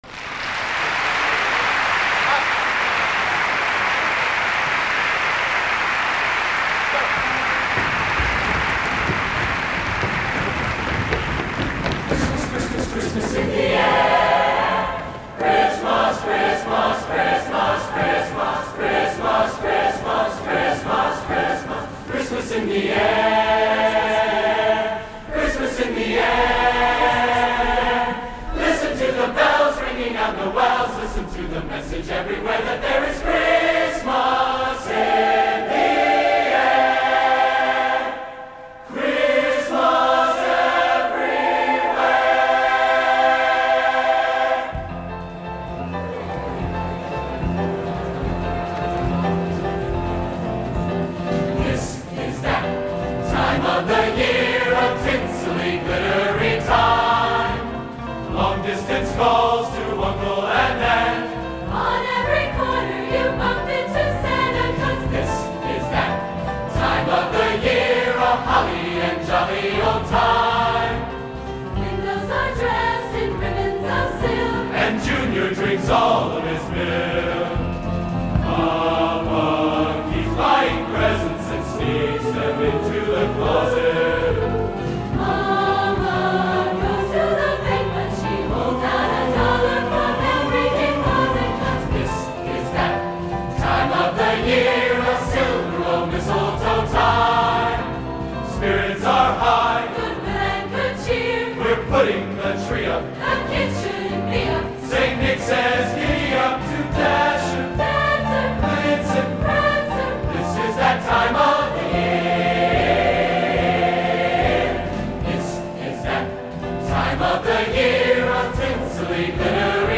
Genre: | Type: Christmas Show |